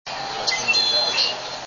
Red-winged Blackbird
Four part song variation, "oa-ka-LEE--ya," Alabama Jacks Restaurant, Key Largo, FL, 4/28/05 (7kb)
blackbird_red-winged_879.wav